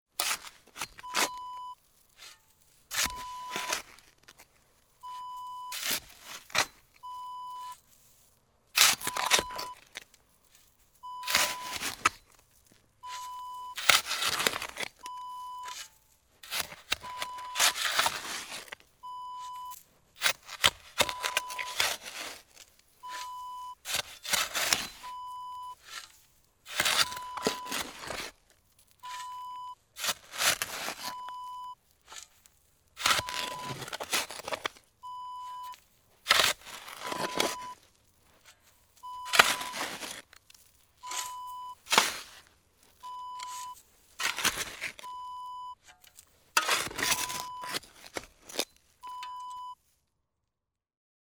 Shovel digging dirt sound effect .wav #3
Description: The sound of a shovel digging rocky dirt
Properties: 48.000 kHz 24-bit Stereo
A beep sound is embedded in the audio preview file but it is not present in the high resolution downloadable wav file.
Keywords: shovel, shoveling, dirt, sand, sandy, earth, dig, digging, scoop, scooping, throw, throwing, hit, hitting, pour, pouring, soil, ground
shovel-digging-preview-3.mp3